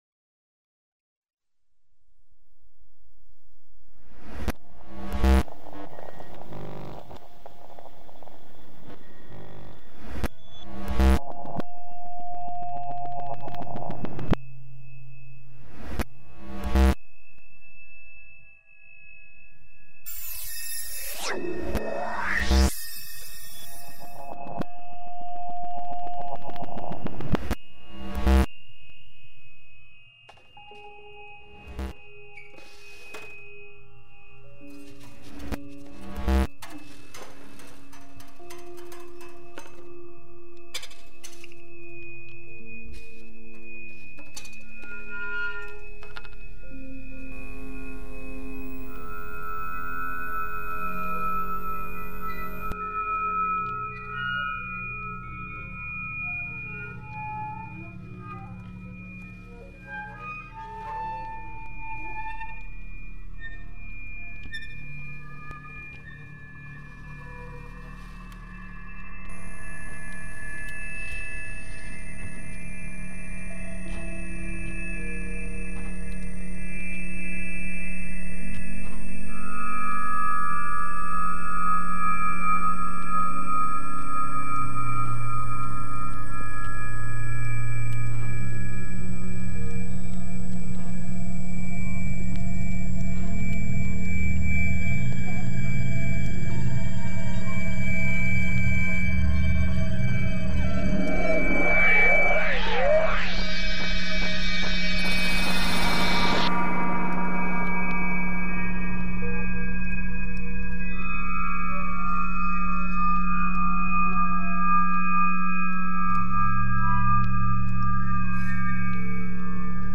File under: Electroacoustic / Experimental
The resulting sounds were then assembled in a studio.